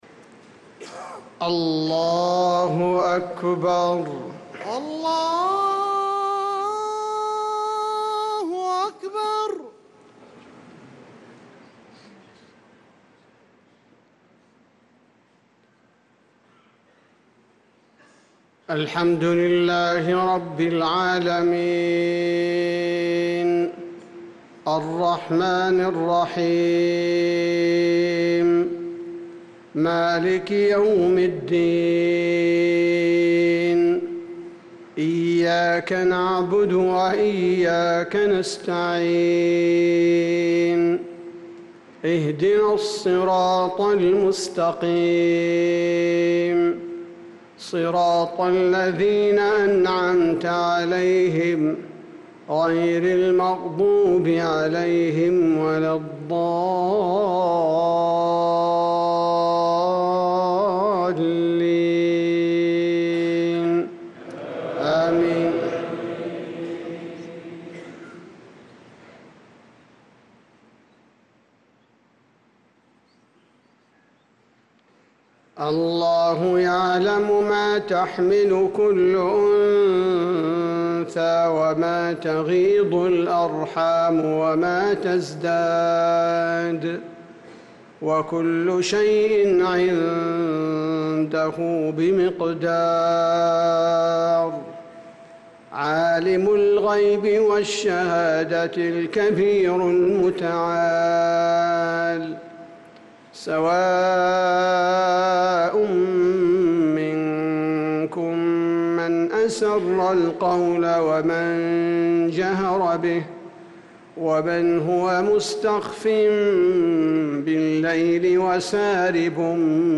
صلاة المغرب للقارئ عبدالباري الثبيتي 30 ذو الحجة 1445 هـ
تِلَاوَات الْحَرَمَيْن .